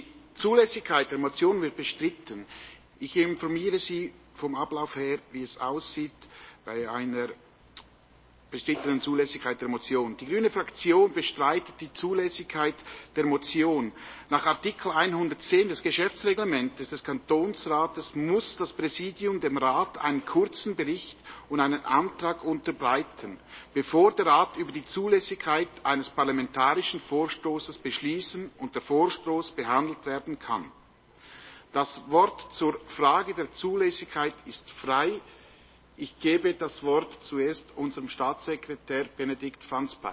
Session des Kantonsrates vom 14. und 15. Februar 2022